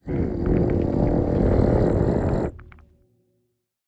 angry_1.ogg